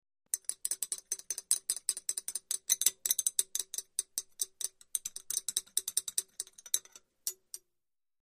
BeakerStirLiquid PE266001
Beaker; Stir 1; A Metal Stirrer Briskly Stirring A Liquid In A Glass Beaker; Close Perspective. Pharmacy, Lab.